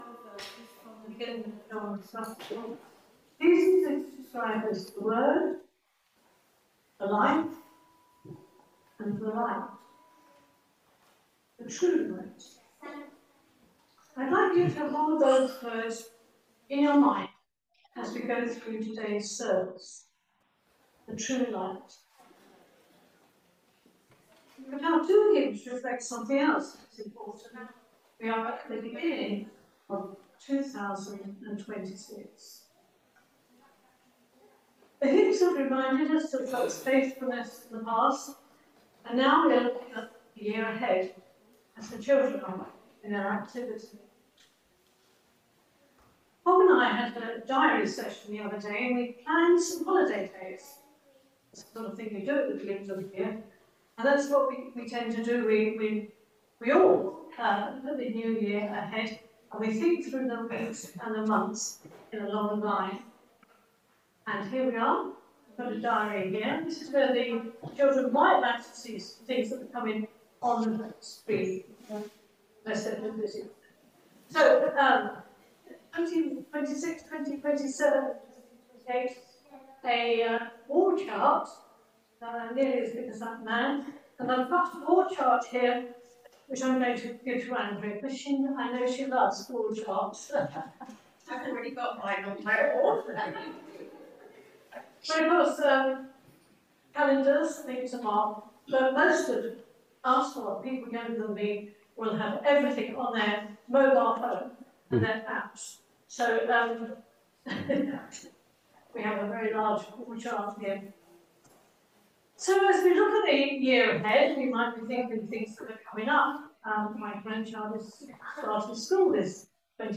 Reading for Morning service John 1. 1–9